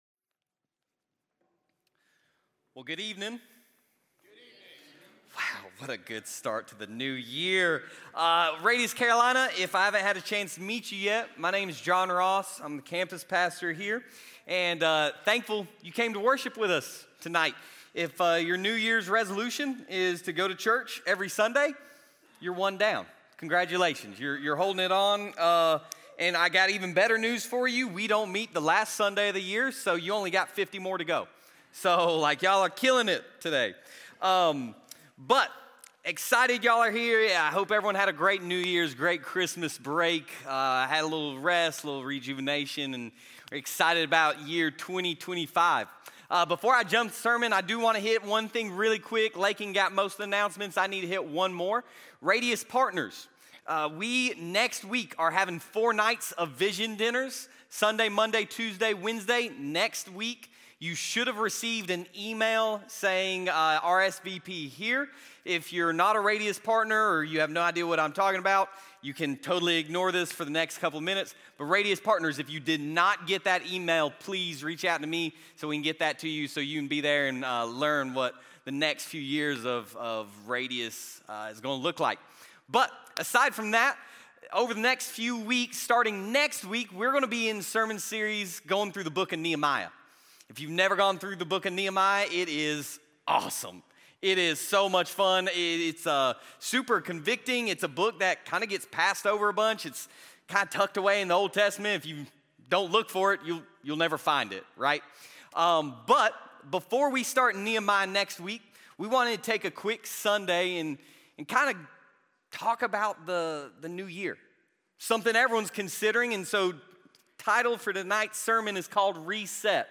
Sermon Library | RADIUS Church
From Campus: "RADIUS Carolina"